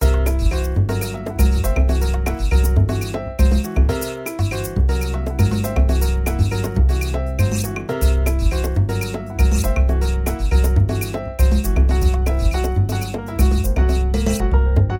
backing tracks
Lydian b7 Mode